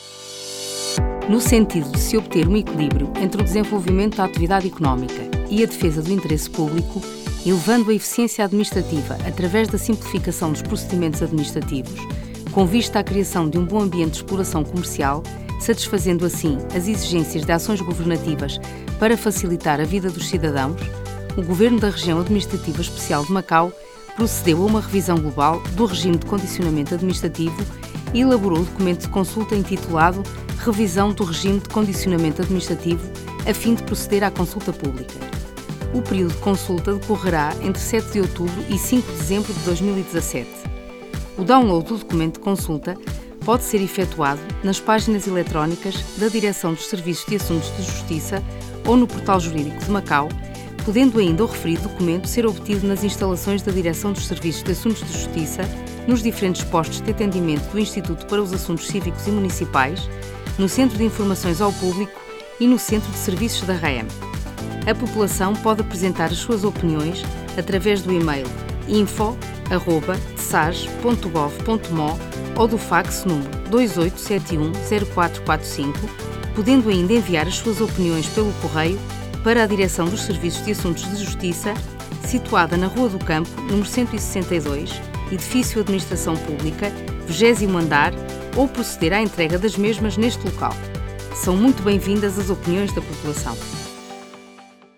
附件其他資訊_行政准照_電台宣傳聲帶_pt_95s.mp3